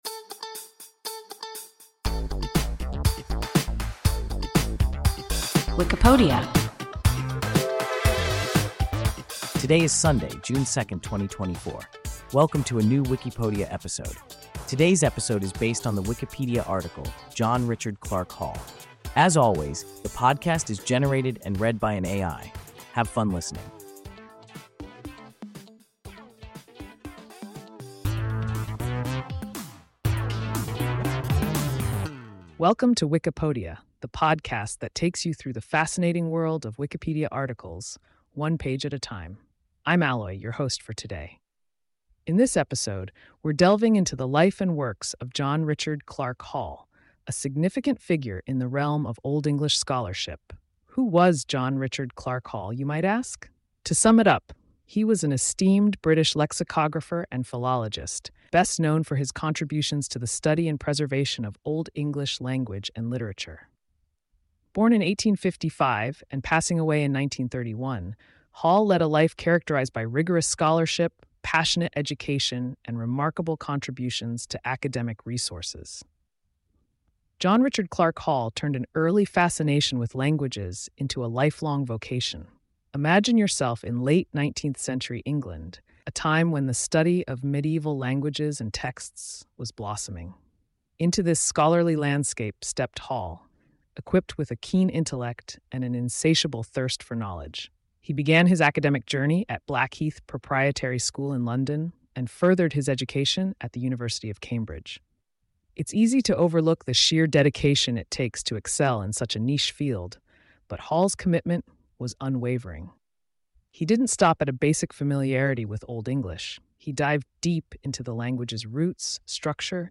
John Richard Clark Hall – WIKIPODIA – ein KI Podcast